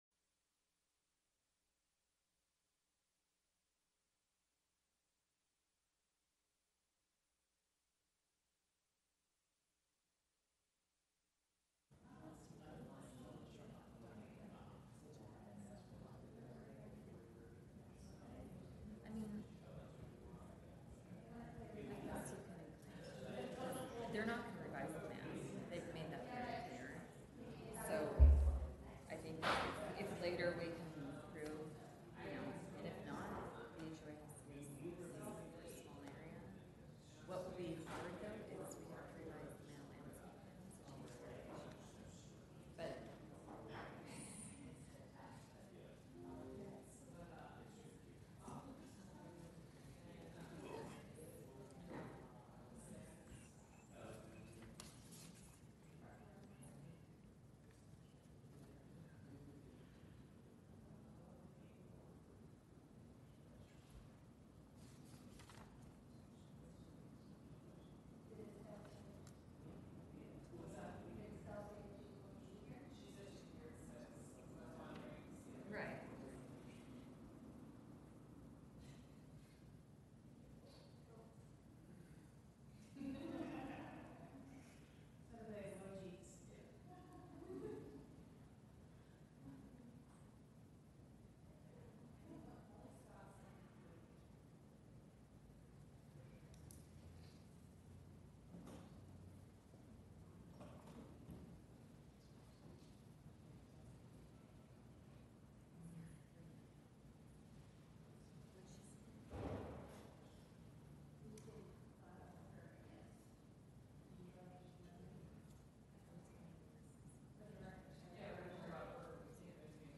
City of Ventura: City of Ventura Audio Podcast Author: City of Ventura Language: en-us Genres: Government Contact email: Get it Feed URL: Get it iTunes ID: Get it Get all podcast data Listen Now... Planning Commission Meeting